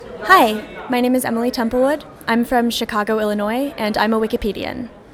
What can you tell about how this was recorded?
captured with Zoom H1 Handy Recorder